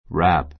（ ⦣ wr- というつながりでは w は発音しない）